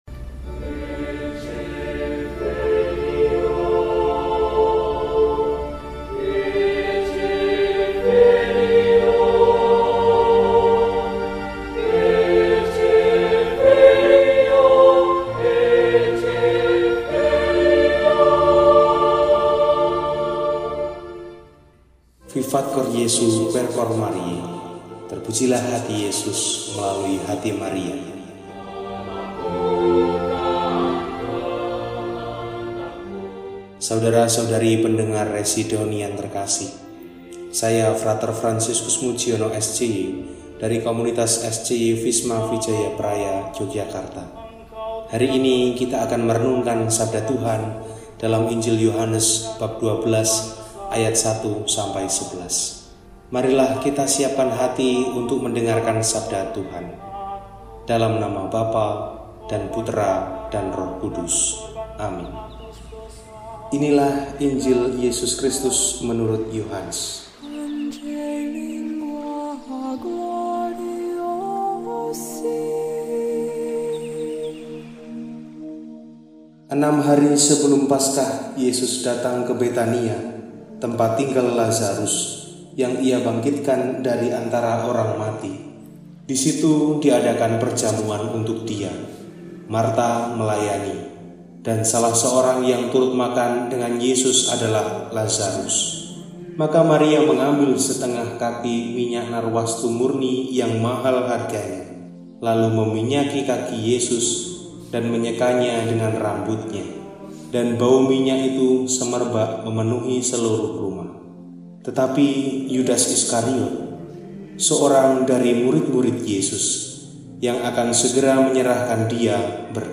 Senin, 14 April 2025 – Hari Senin dalam Pekan Suci – RESI (Renungan Singkat) DEHONIAN